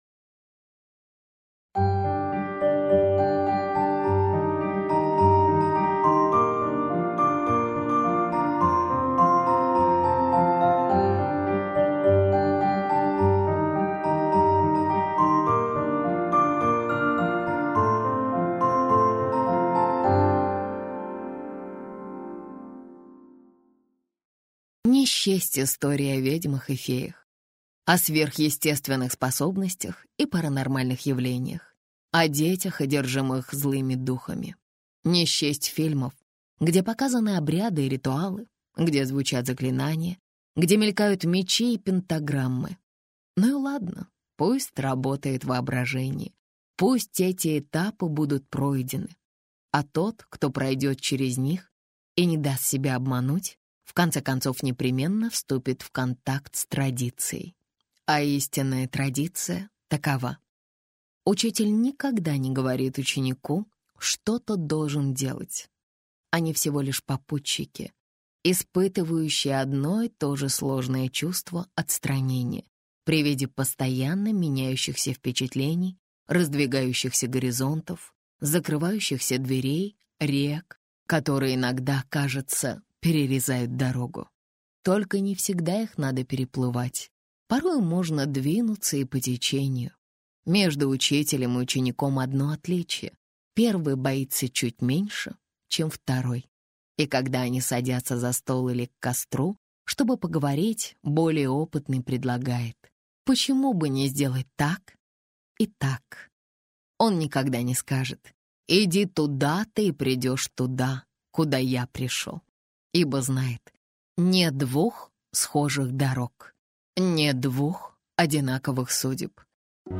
Аудиокнига Ведьма с Портобелло - купить, скачать и слушать онлайн | КнигоПоиск